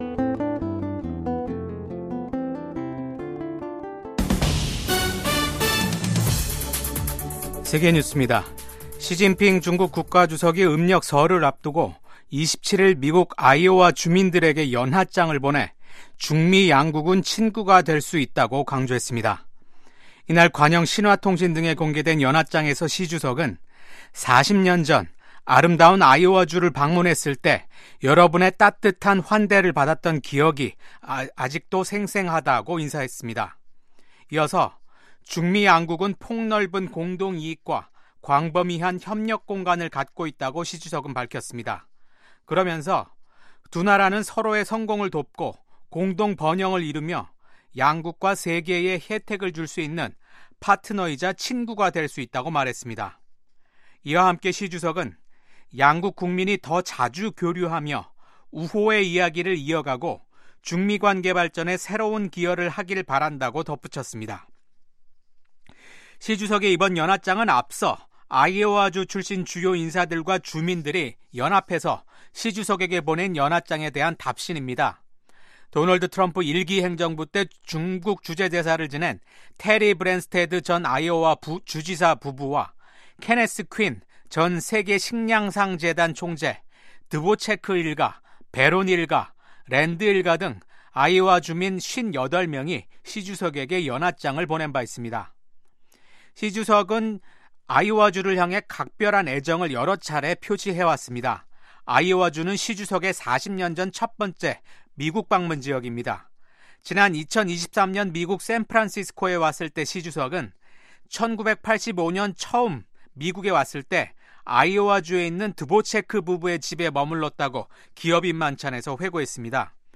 VOA 한국어 아침 뉴스 프로그램 '워싱턴 뉴스 광장'입니다. 북한은 김정은 국무위원장이 참관한 가운데 서해상으로 전략순항미사일을 시험 발사했습니다. 미국 하원에서 미한 동맹의 중요성을 재확인하는 결의안이 발의됐습니다. 영국 국방부는 러시아에 파병된 북한군의 3분의 1 이상이 사망하거나 부상한 것으로 추정했습니다.